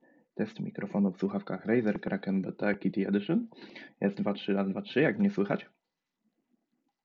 Ten, choć nada się do prostych rozmów, to jednak nic więcej nie zaoferuje. Sam poziom głośności jest dość niski i o ile w cichym pomieszczeniu radzi sobie nieźle, o tyle w hałasie nie sprawdzi się dobrze. Próbka z mikrofonu poniżej.